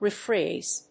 音節re・phrase 発音記号・読み方
/rìːfréɪz(米国英語), ri:ˈfreɪz(英国英語)/